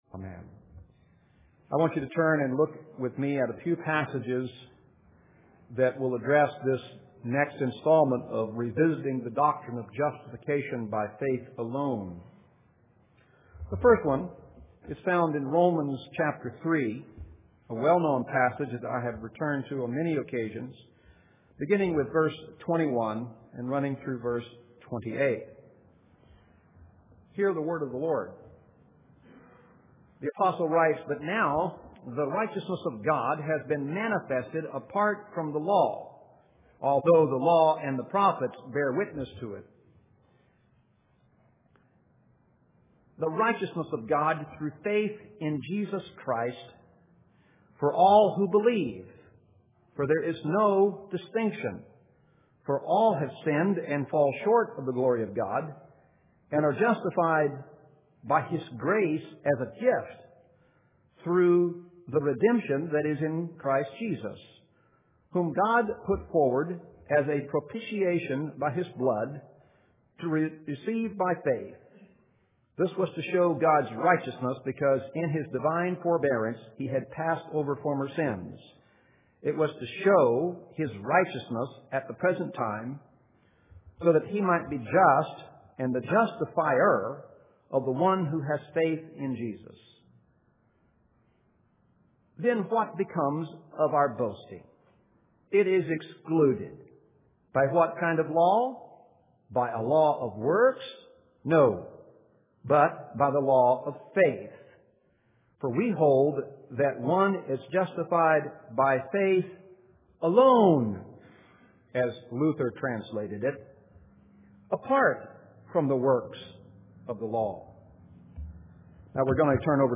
Text: Romans 3:21-28; 4:4-5; Gal. 2:16-21 Sermon Notes Post navigation ← Previous Post Next Post →